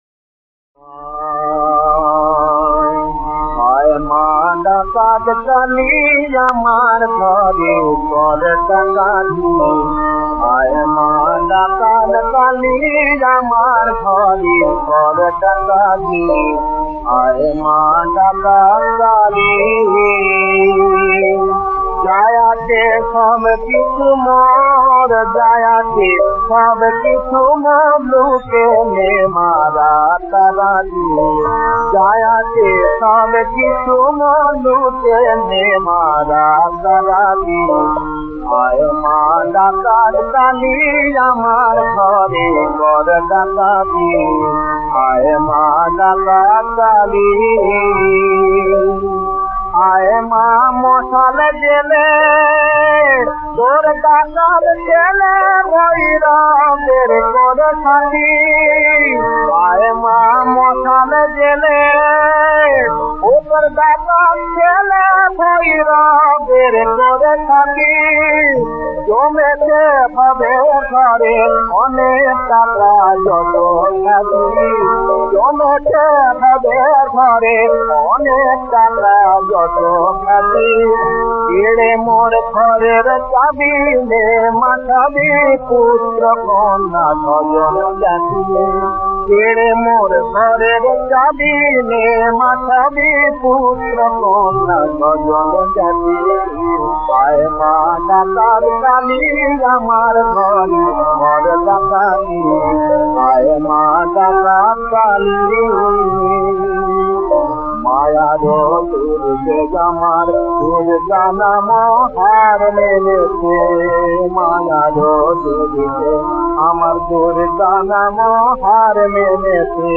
শ্যামাসঙ্গীত।
• বিষয়াঙ্গ: ধর্মসঙ্গীত। সনাতন হিন্দুধর্ম। শাক্তসঙ্গীত। আত্মনিবরদন
• তাল: দ্রুত দাদরা
• গ্রহস্বর: ধ্